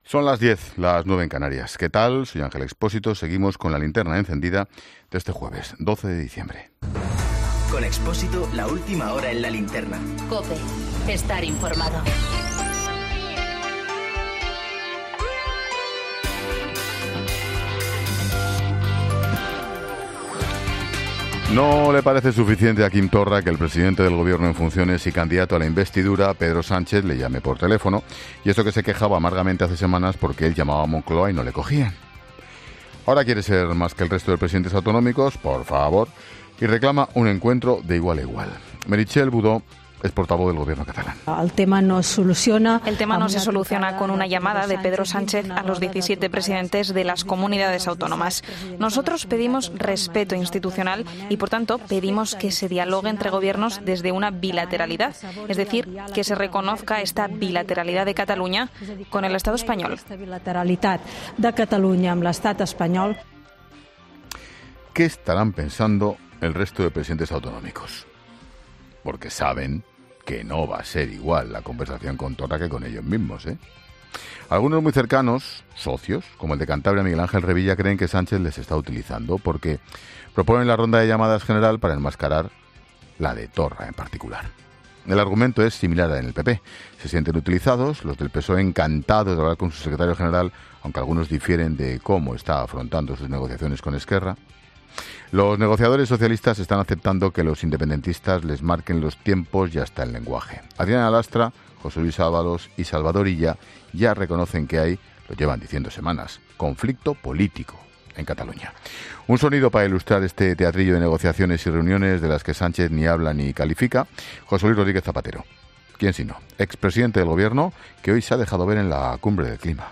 Boletín de noticias de COPE del 12 de diciembre de 2019 a las 22.00 horas